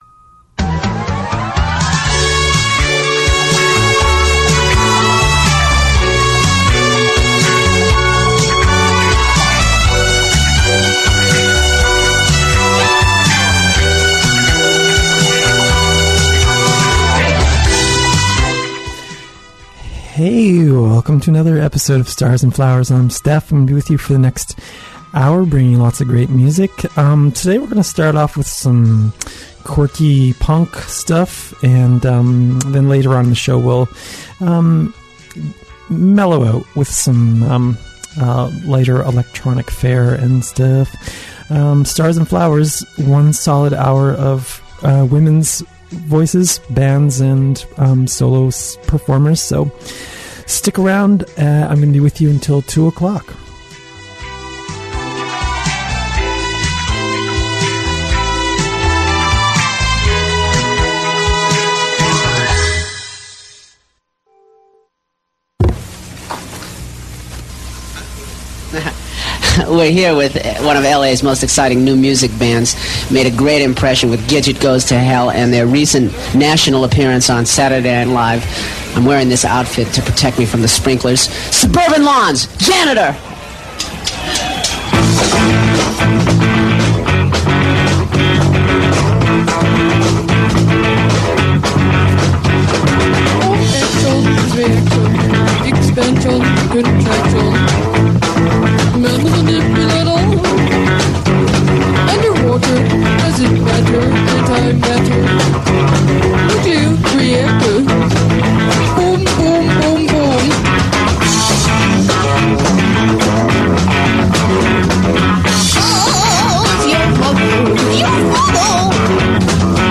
A weekly one-hour all-female artist, open format show music program.